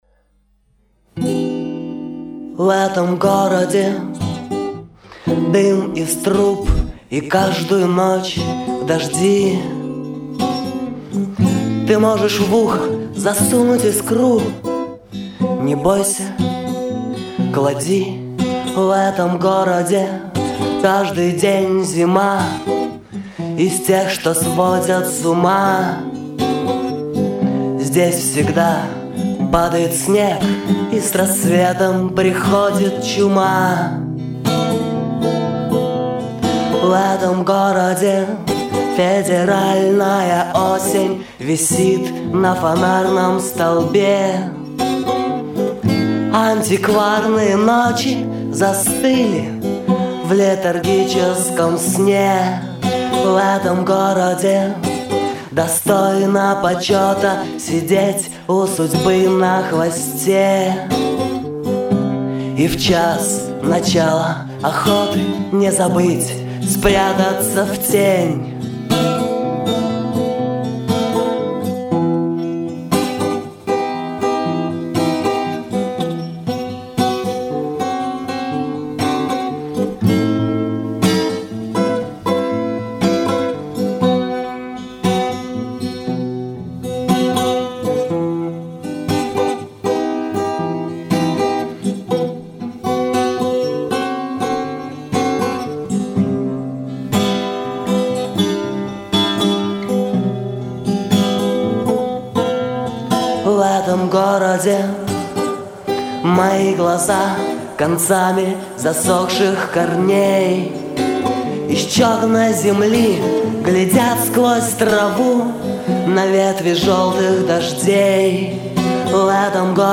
акустика